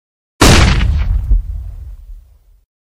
The flash is a high-energy audio clip commonly used in memes, TikToks, and YouTube shorts Reactions sound and meme culture.
Featuring a punchy beat with iconic "flash", it's a staple in modern Reactions sound and meme culture.